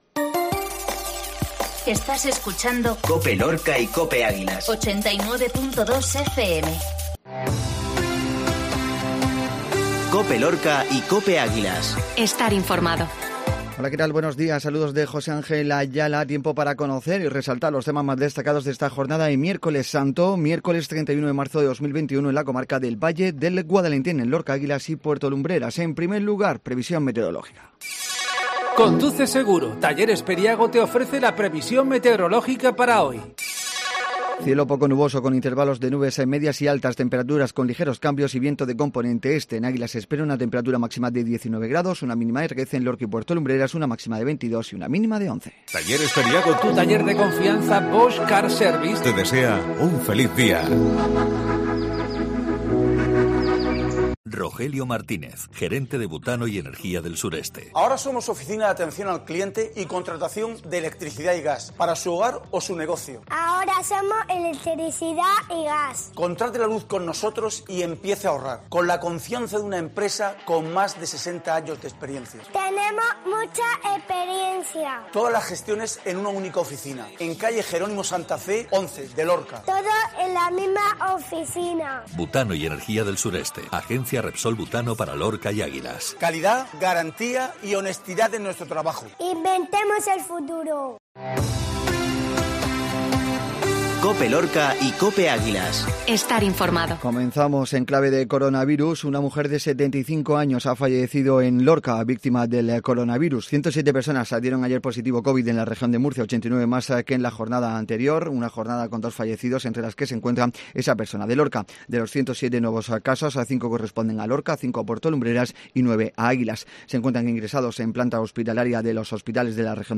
INFORMATIVO MATINAL MIÉRCOLES